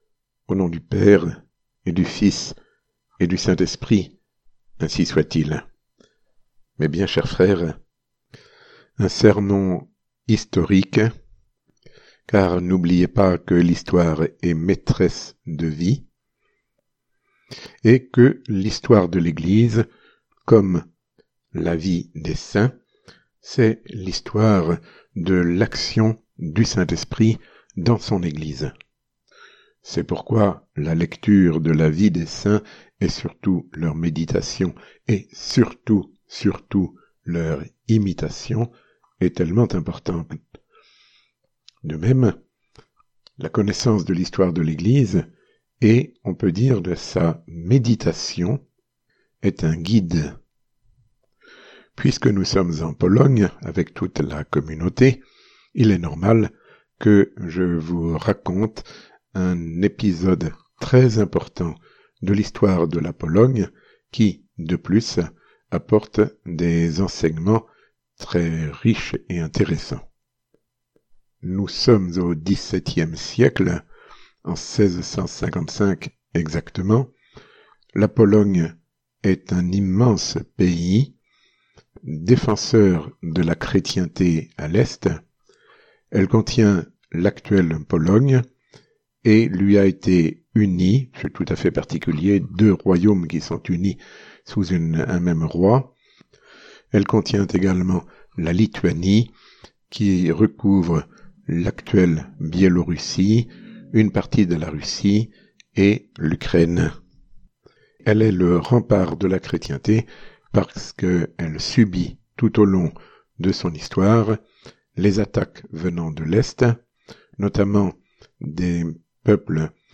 Sermon ~ Notre Dame de Czestochowa et la Fidélité catholique